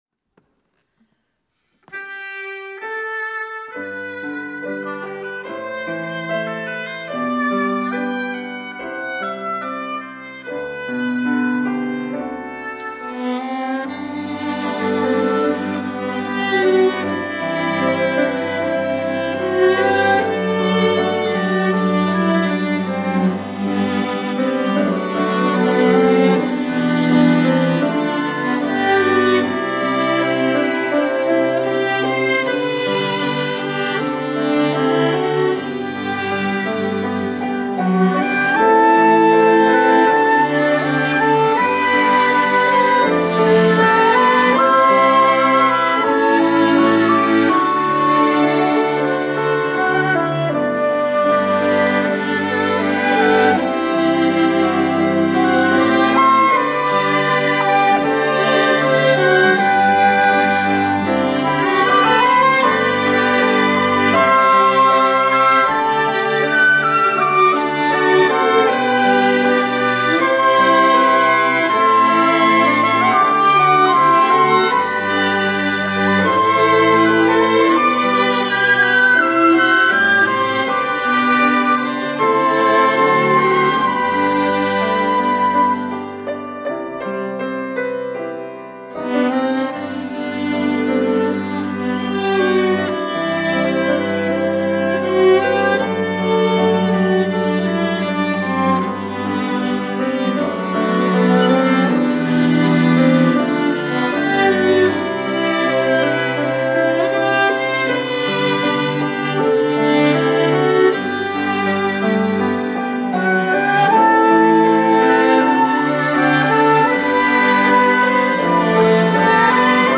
특송과 특주 - 여기에 모인 우리
이름 셀라 합주단